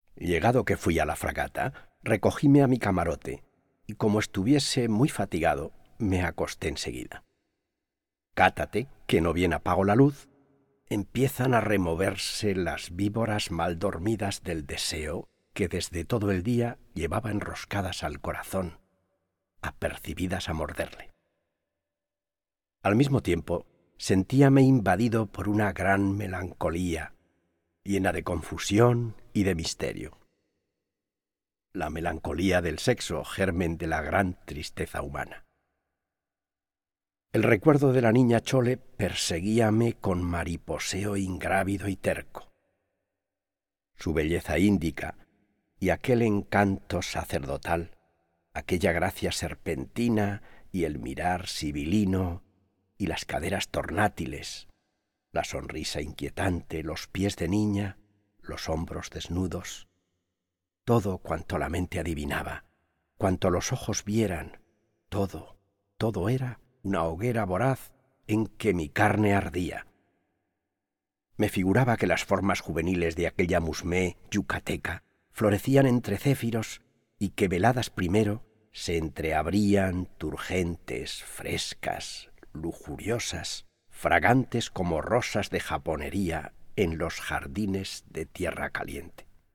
recitar